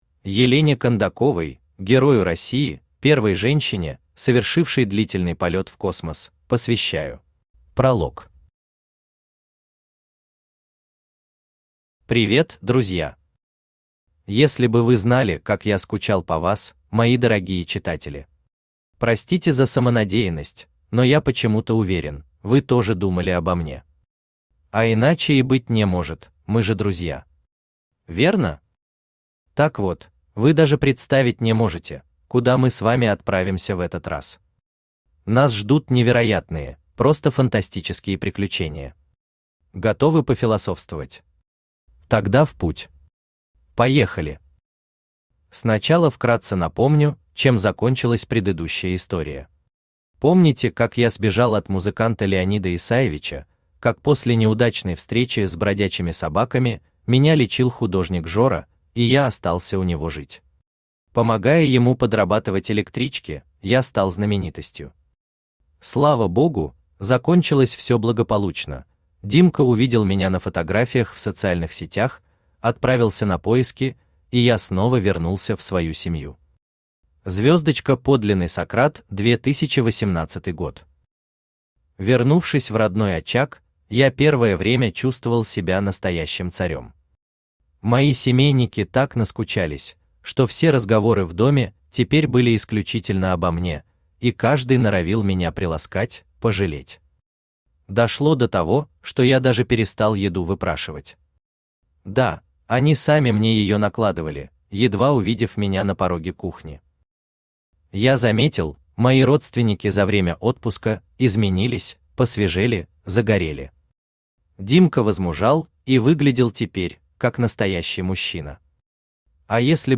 Аудиокнига Сократ выходит на орбиту (записки котонавта) | Библиотека аудиокниг
Aудиокнига Сократ выходит на орбиту (записки котонавта) Автор Михаил Самарский Читает аудиокнигу Авточтец ЛитРес.